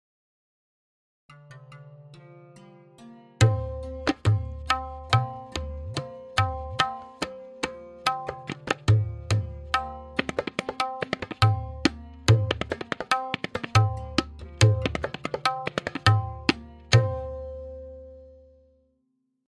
Sam-to-Sam Bedam Tihai with Theka and Lahra
M4.2-Bedam-Theka-Lehra.mp3